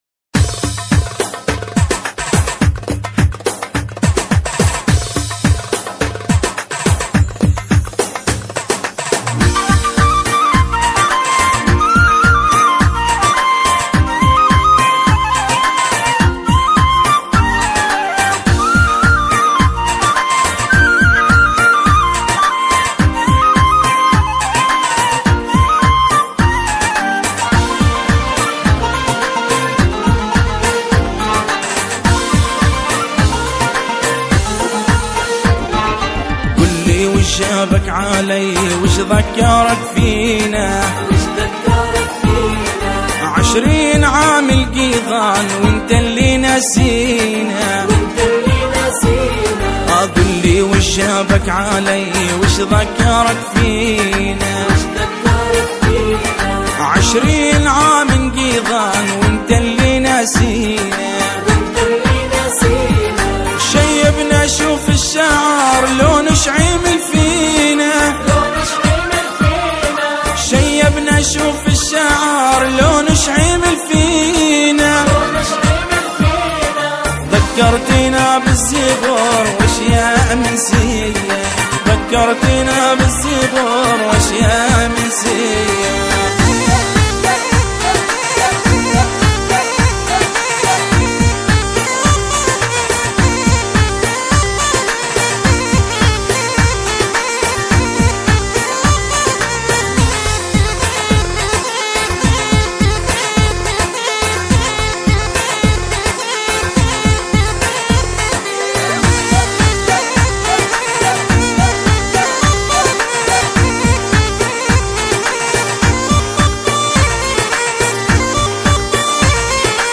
أغاني فلسطينيه